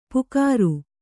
♪ pukāru